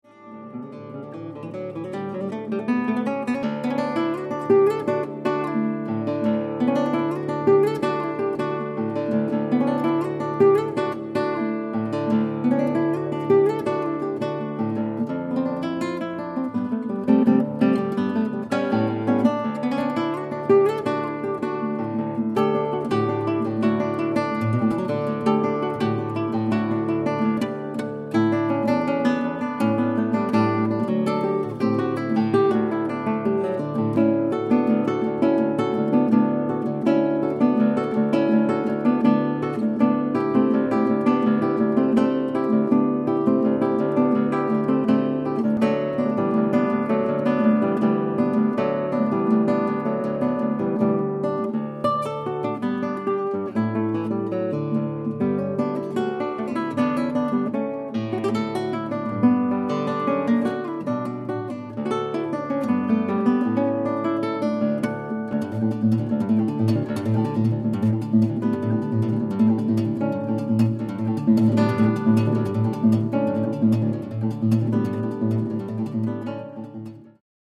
Solo Guitar